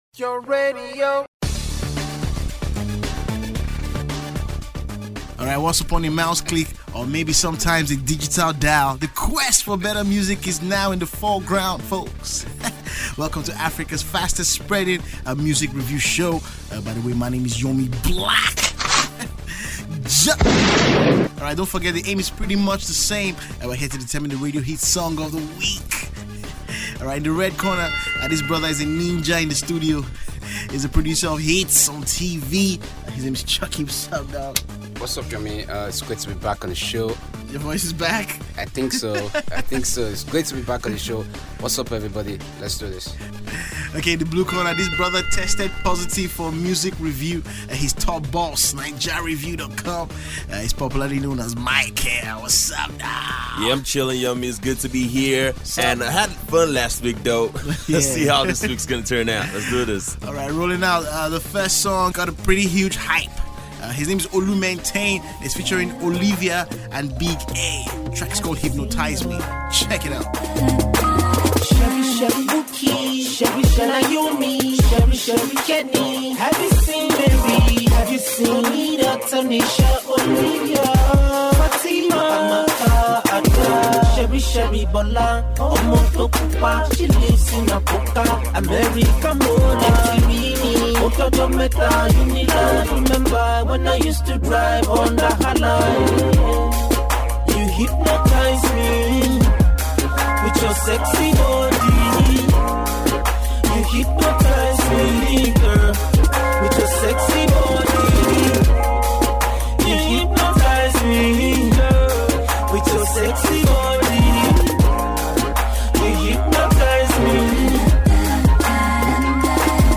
A few years ago, I read a book ” The enemy called average”, this book points out the dangers of being just average, and to be honest Olu Maintain needs a copy. In this weeks episode, the panelist have a better insight to Olu’s problem…find out Here